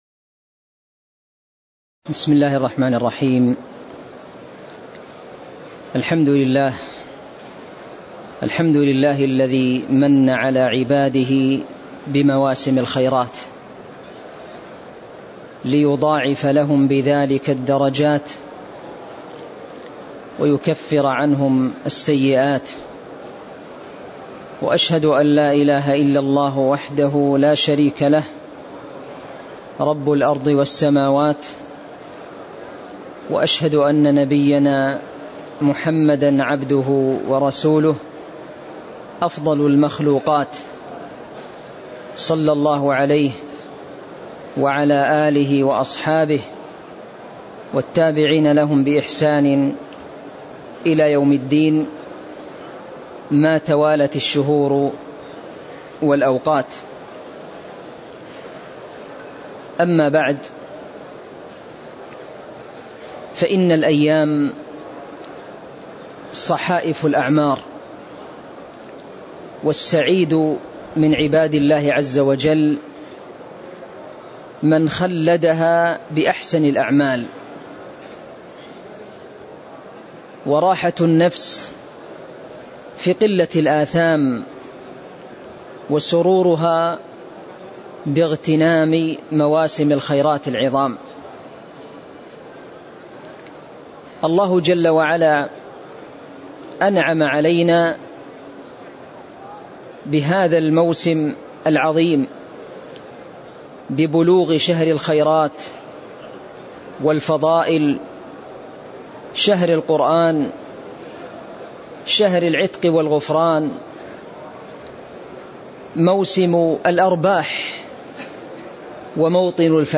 تاريخ النشر ٥ رمضان ١٤٤٣ هـ المكان: المسجد النبوي الشيخ